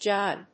/dʒάɪn(米国英語), dʒeɪn(英国英語)/